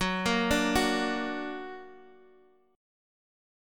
Gb+ chord